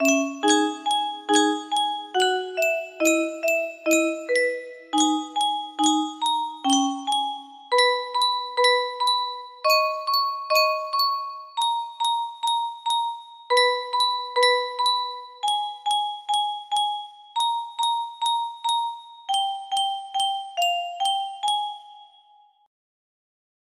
Clone of G6# college-v2 music box melody
Full range 60